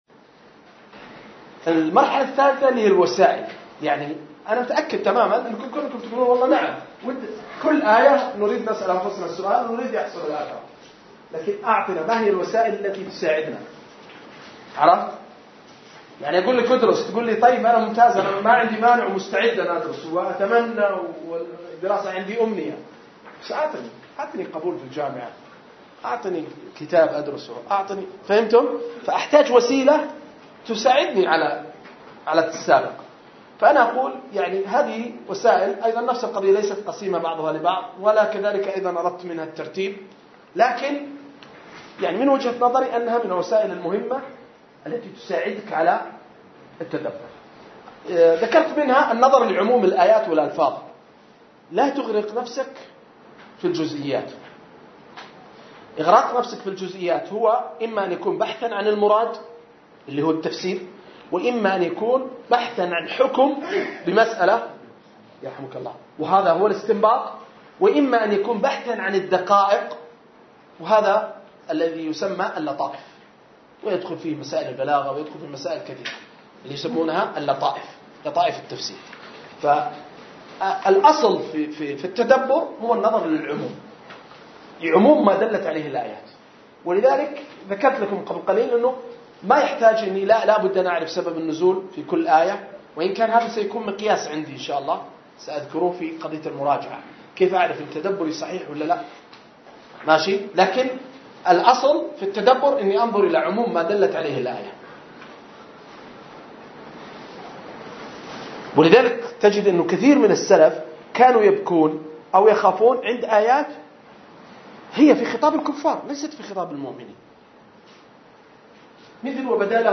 دروس متنوعة